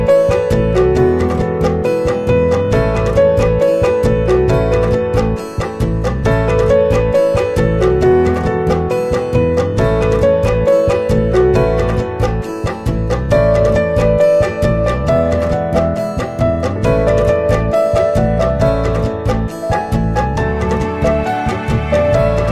Category: Bollywood Ringtones